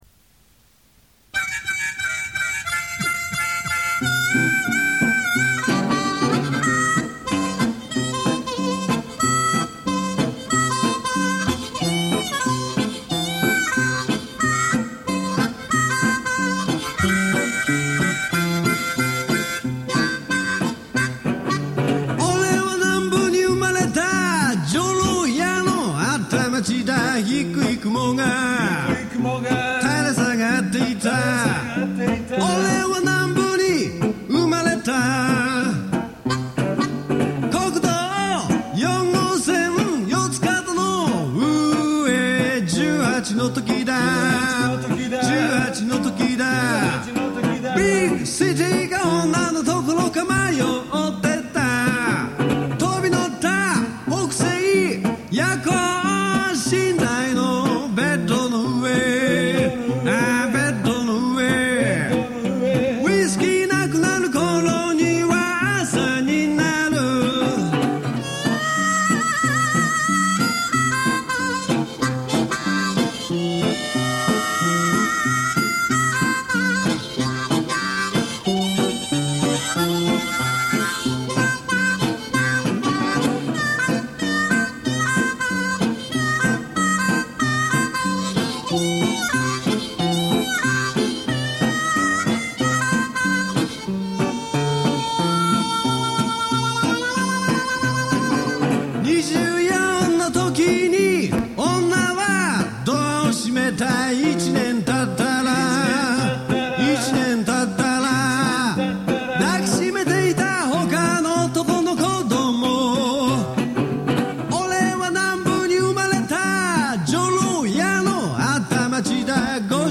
エレキギターをドブロに持ち替えて、お得意のボトルネック奏法。
某貸しスタジオに録音機材一式を持ち込み、録音。